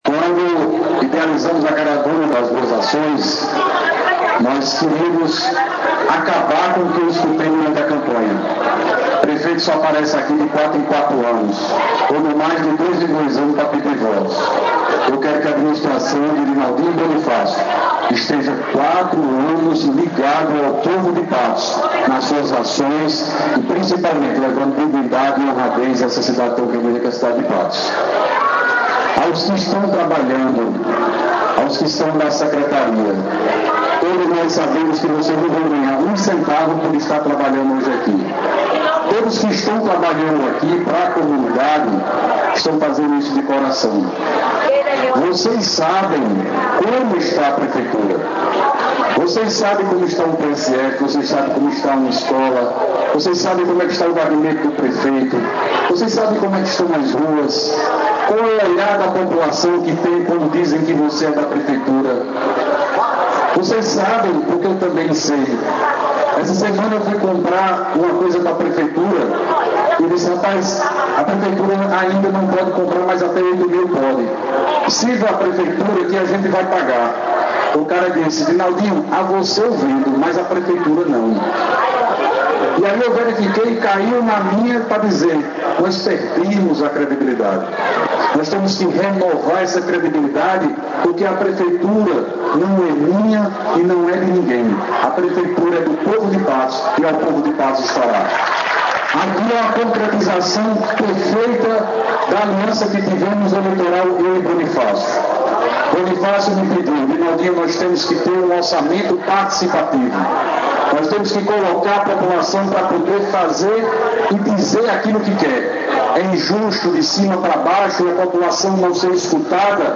Fala do prefeito, Dinaldinho Wanderley, na abertura da Caravana das Boas Ações